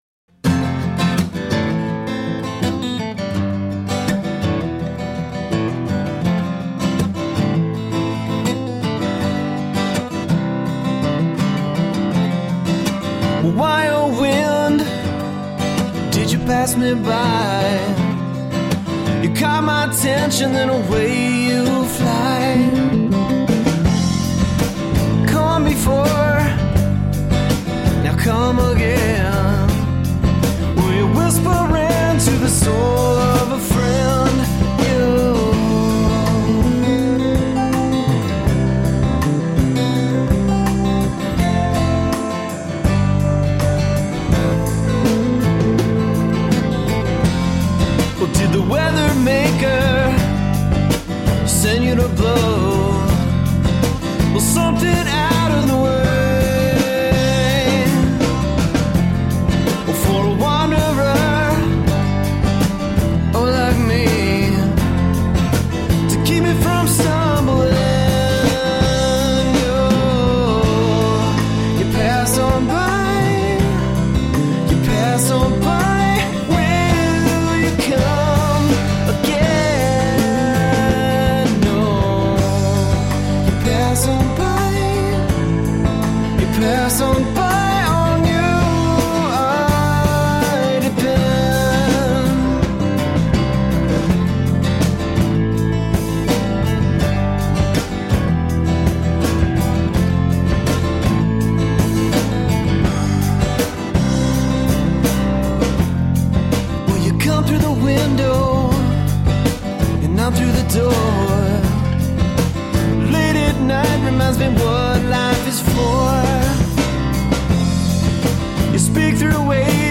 Tagged as: Alt Rock, Folk-Rock